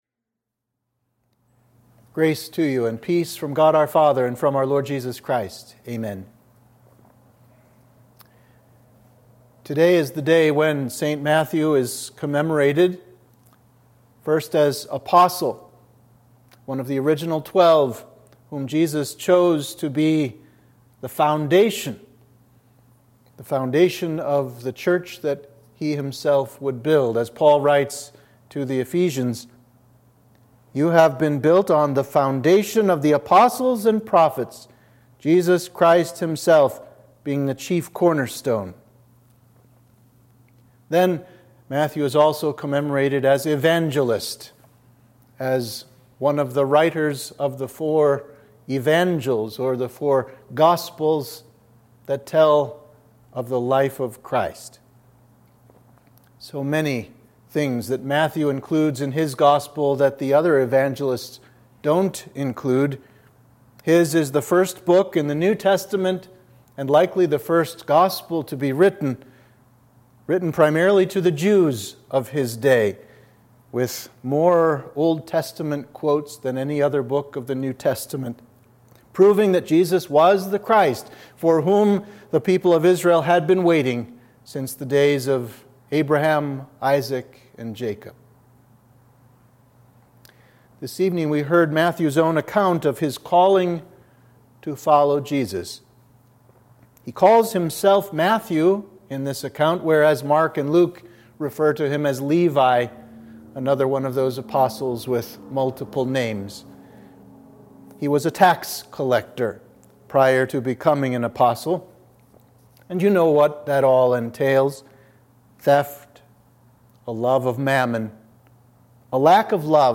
Sermon for the Festival of St. Matthew